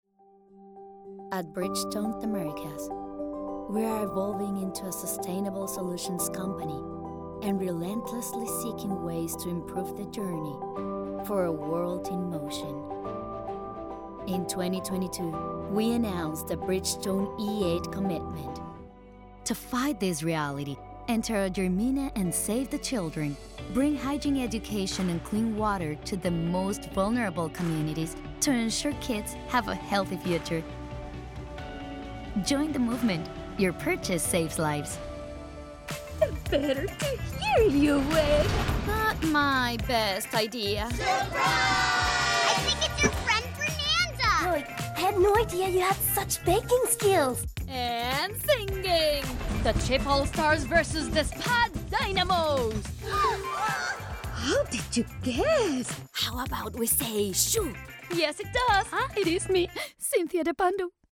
A selection of voiceover demos in English is available here.
Corporate video - English